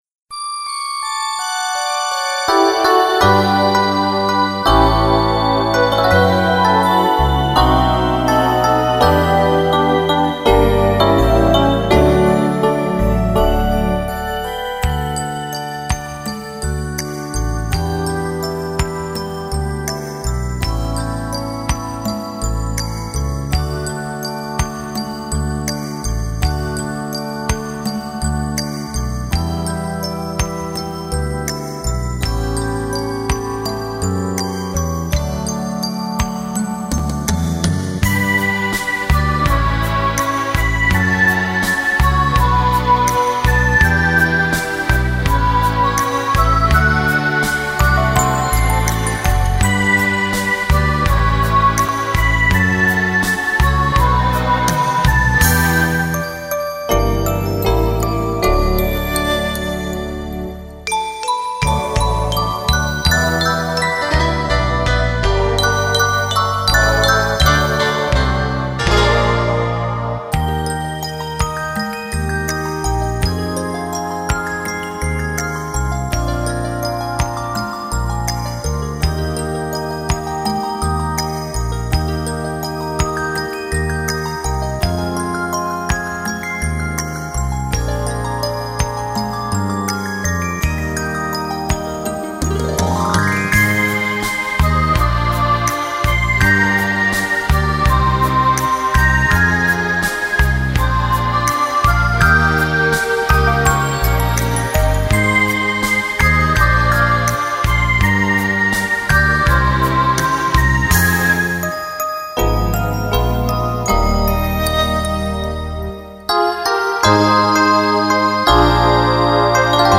Рождество - это праздник света - песня